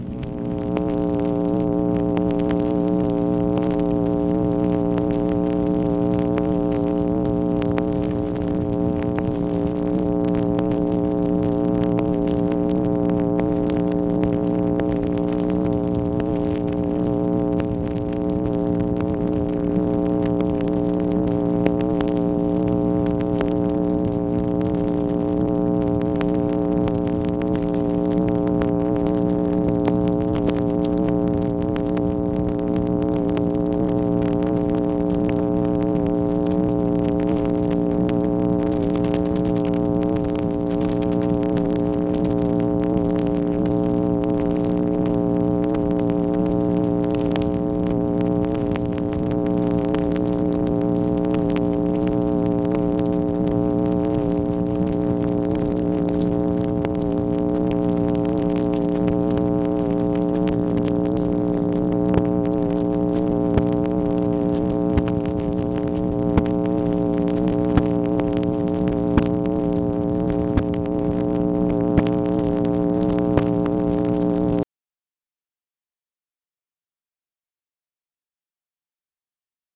MACHINE NOISE, August 5, 1964
Secret White House Tapes | Lyndon B. Johnson Presidency